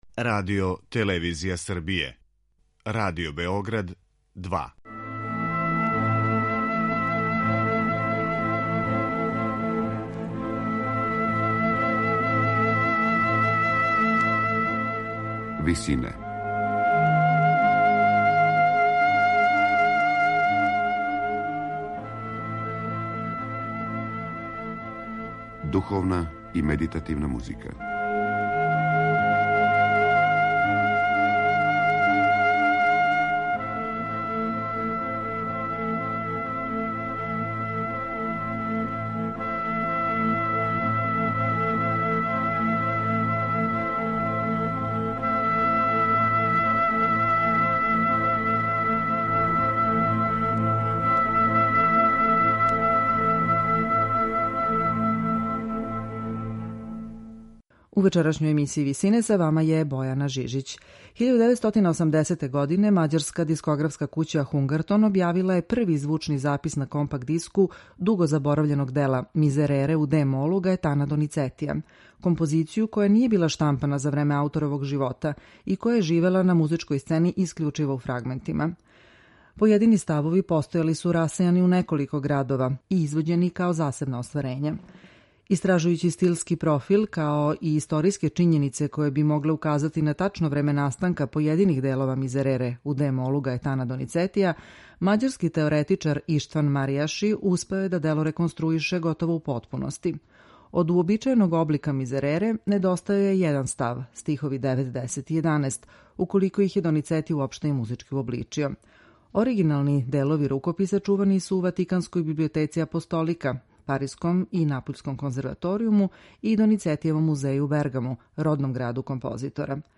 На крају програма, у ВИСИНАМА представљамо медитативне и духовне композиције аутора свих конфесија и епоха.
хора и оркестра